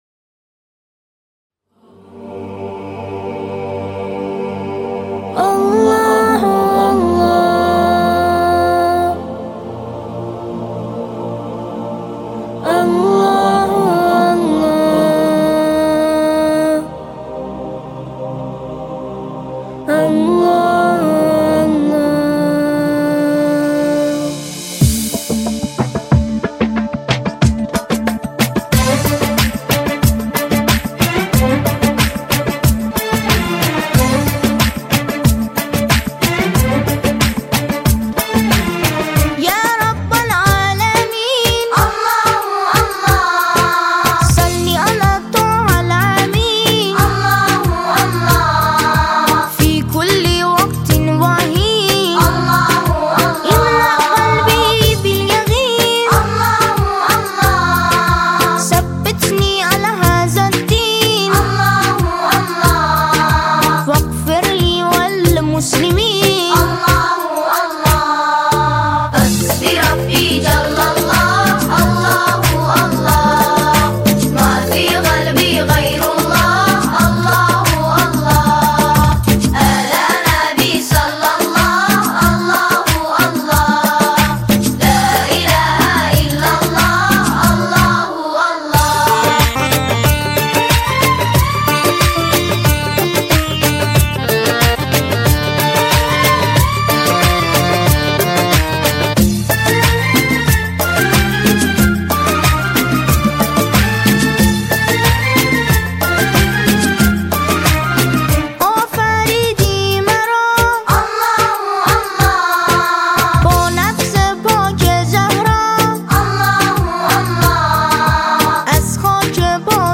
ژانر: سرود ، سرود مذهبی ، سرود مناسبتی